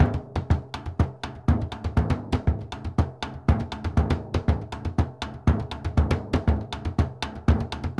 Bombo_Merengue 120_2.wav